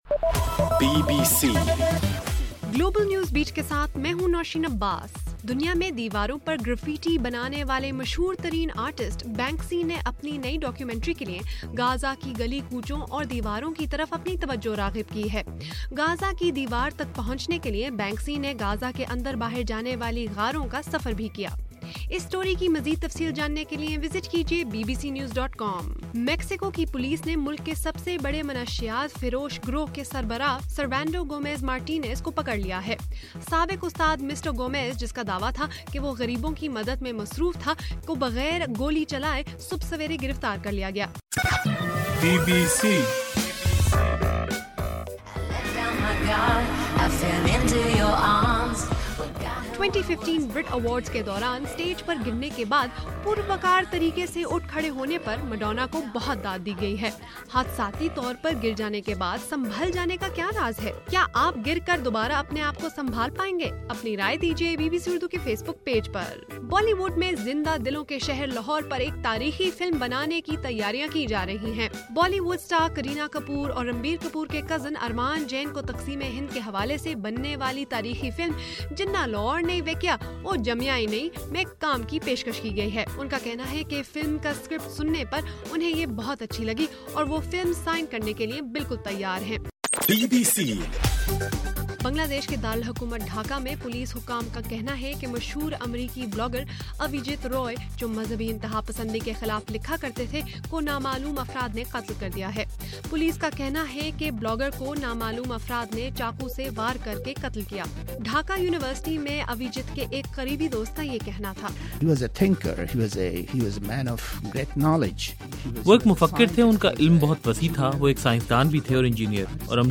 فروری 27: رات 10 بجے کا گلوبل نیوز بیٹ بُلیٹن